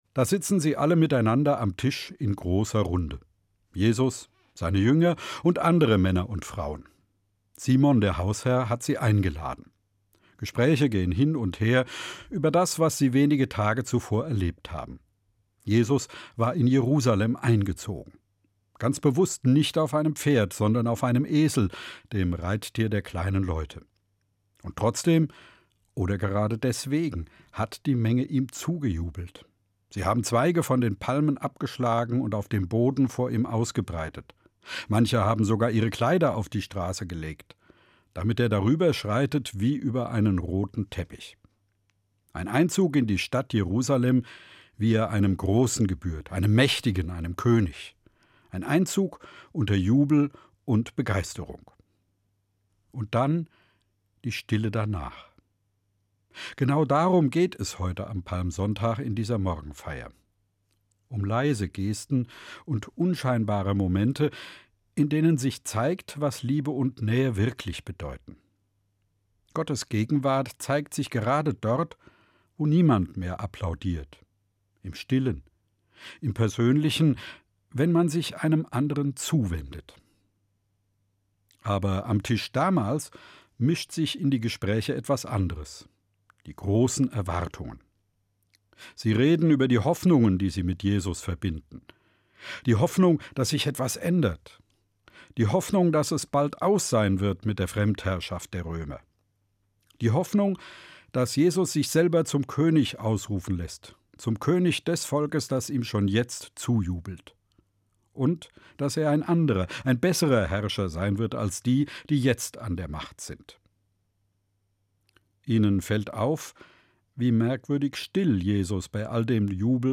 hr2 Evangelische Morgenfeier zum Palmsonntag (29.3.2026): Von Jesu Einzug zur Salbung in Bethanien. Nähe Gottes spüren im Alltag, Glaube im Alltag leben – leise Gesten statt Jubel.